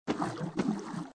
AV_foostep_walkloop_water.ogg